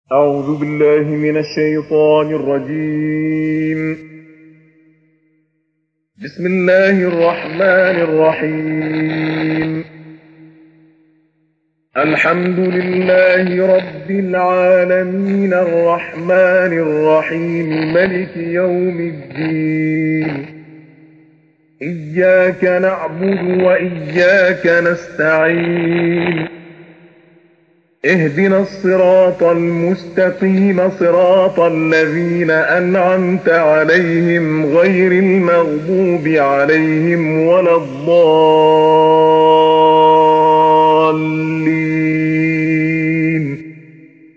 Besucher Rezitationen 2394 Hören 0 Gefällt mir Gefällt mir Teilen Herunterladen Andere Code einbetten Fehler melden ladet...